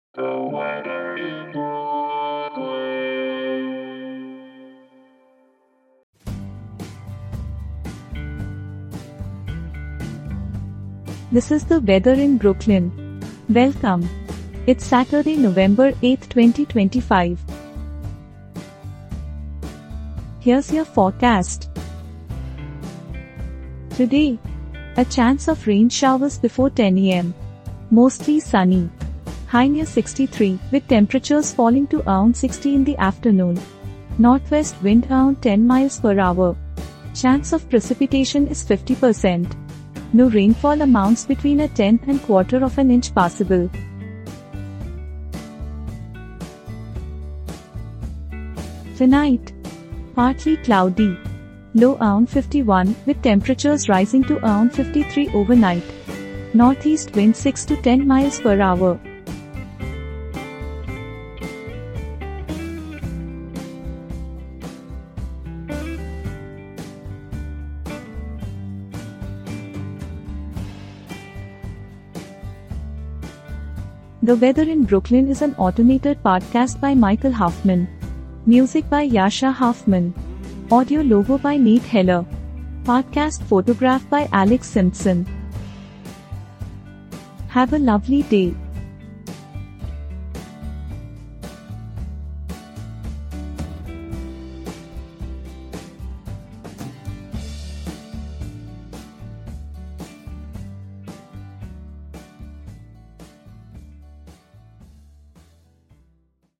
is generated automatically